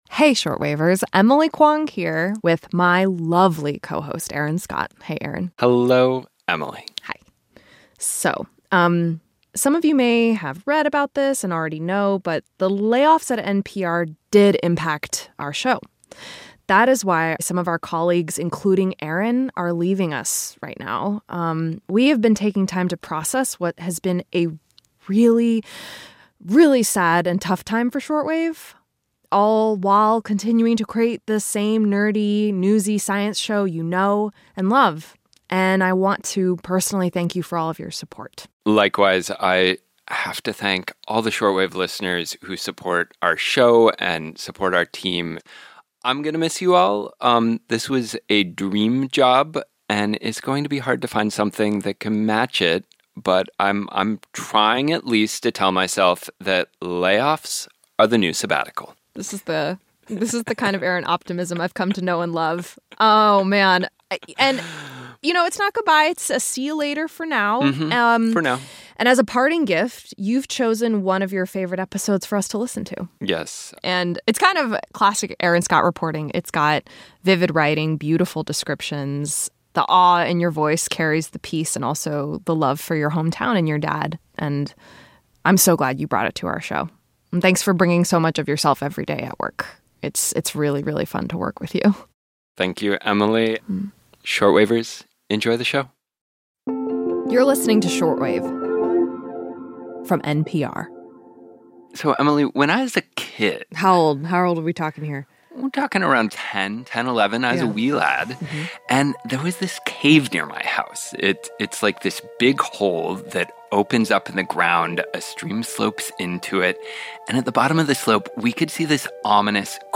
In the toxic waters of Sulphur Cave in Steamboat Springs, Colo. live blood-red worm blobs that have attracted international scientific interest. We don special breathing gear and go into the cave with a team of researchers.